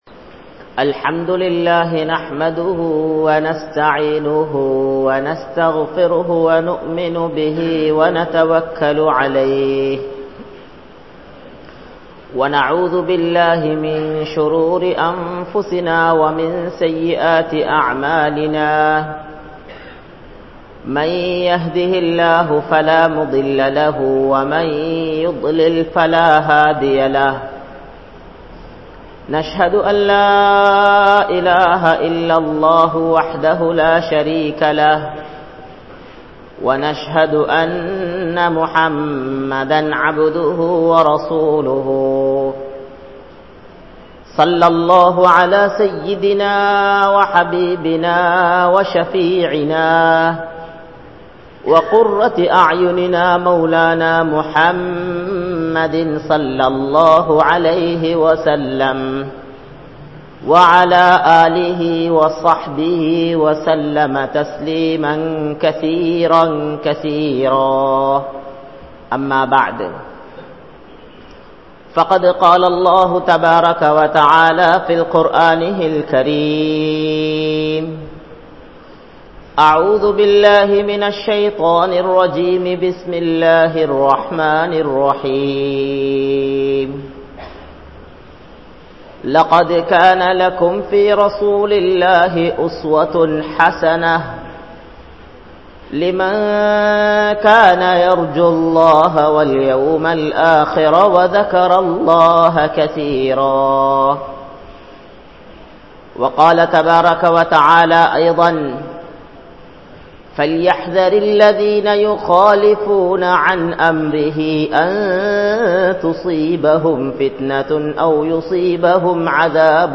Nabi(SAW)Avarhalin Vaalkai (நபி(ஸல்)அவர்களின் வாழ்க்கை) | Audio Bayans | All Ceylon Muslim Youth Community | Addalaichenai
Gorakana Jumuah Masjith